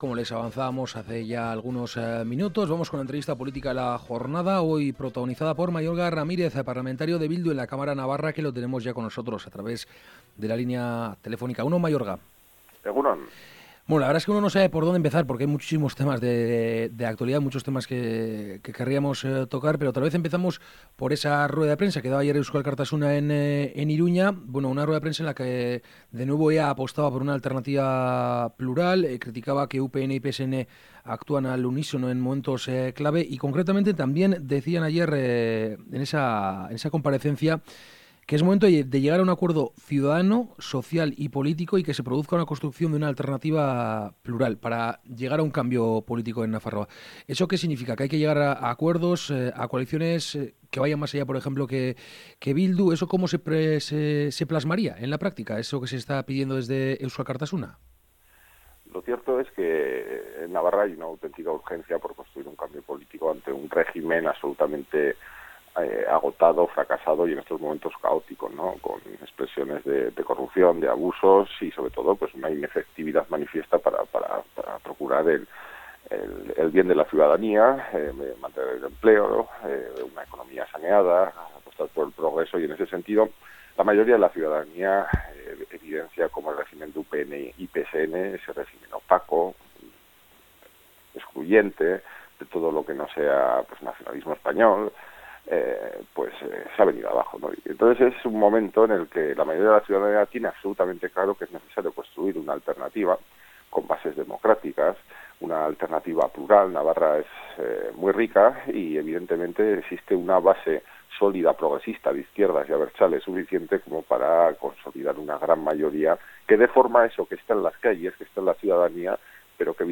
El parlamentario de Bildu en la Cámara navarra ha hablado esta mañana en Infozazpi Irratia en torno a la propuesta que lanzaban ayer desde Eusko Alkartasuna para crear “una alternativa plural” que consiga un cambio político de fonfo en Nafarroa.